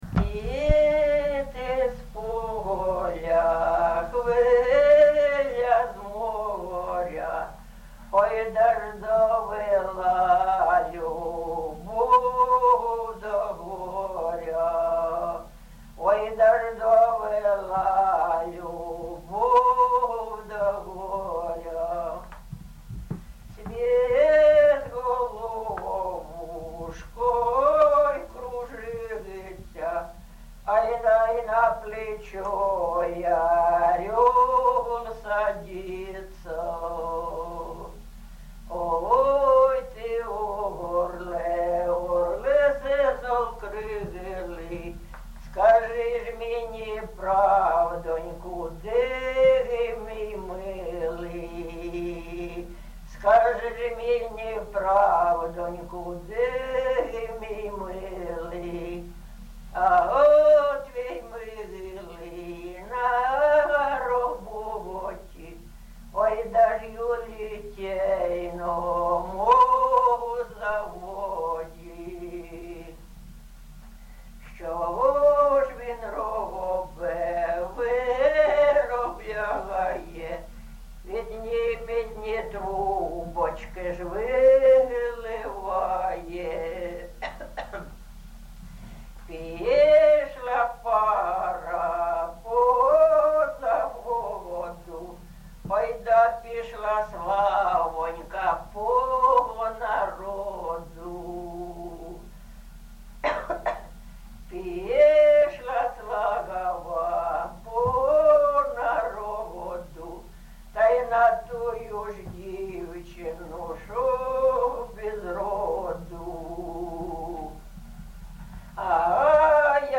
ЖанрПісні з особистого та родинного життя